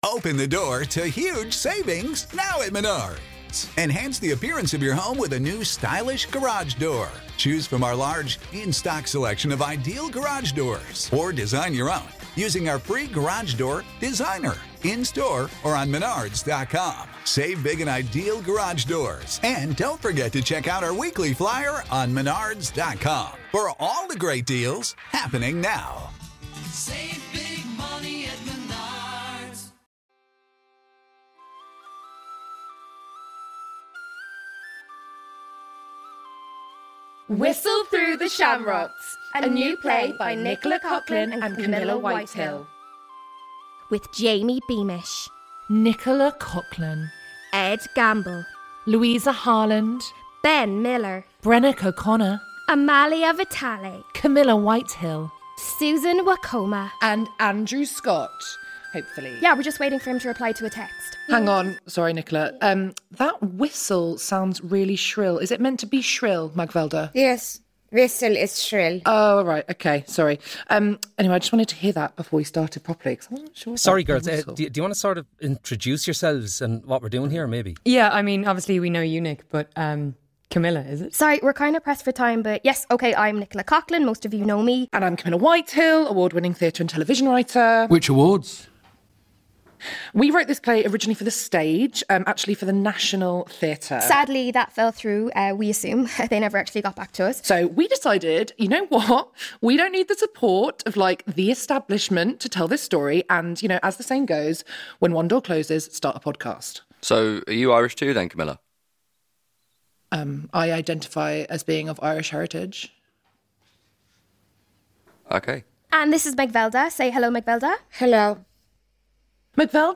A comedy